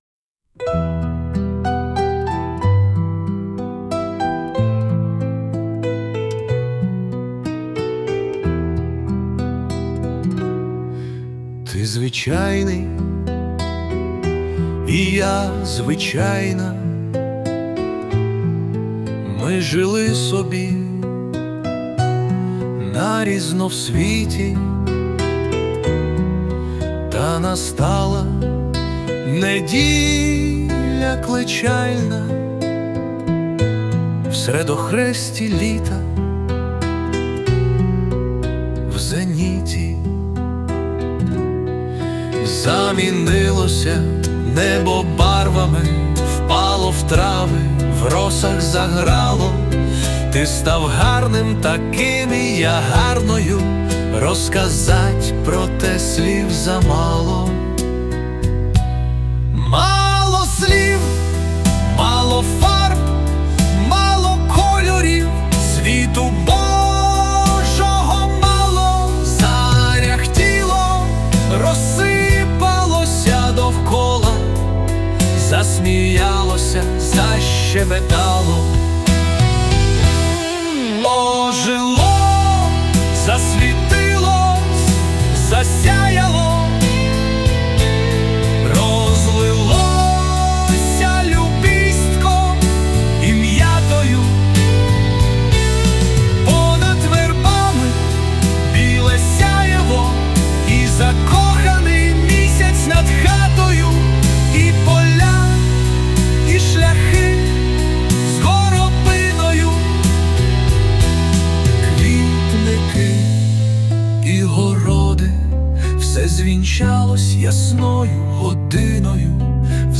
тут ця пісня на мій вірш - з відеорядом...